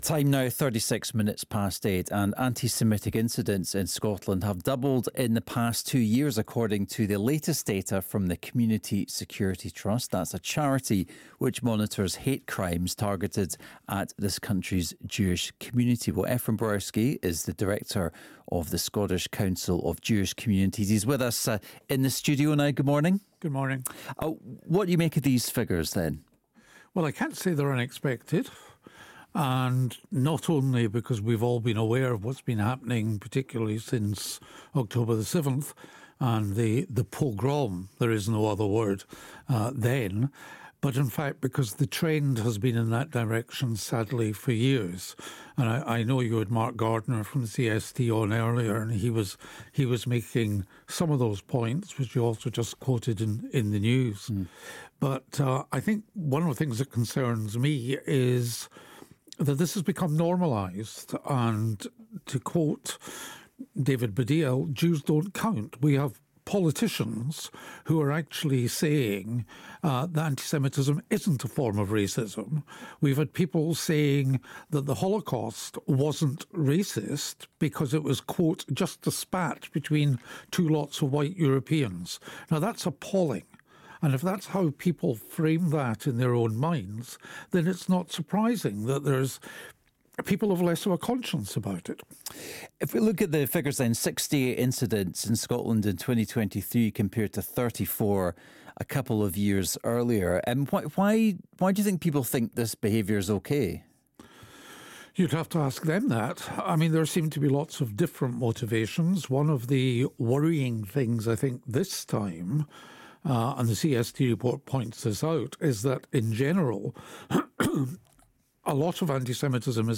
Good Morning Scotland interview about the highest ever annual antisemitic incident statistics in Scotland